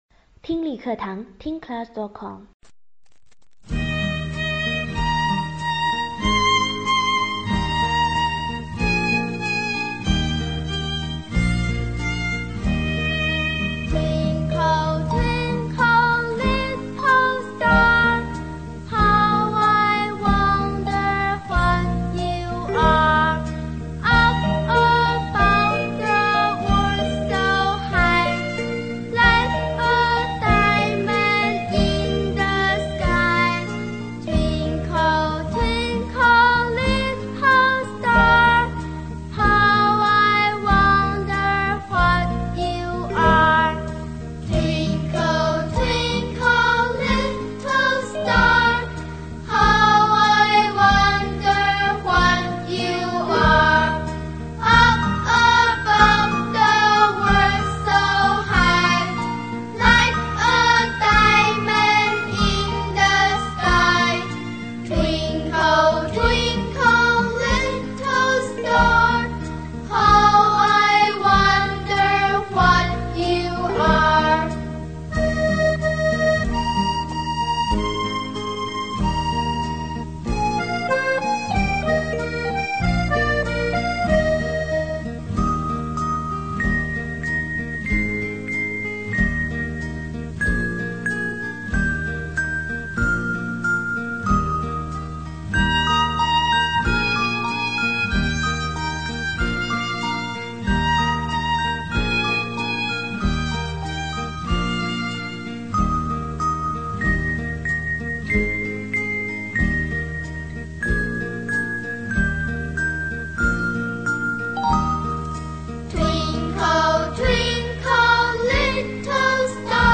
英文儿童歌曲:Twinkle, twinkle, little star 听力文件下载—在线英语听力室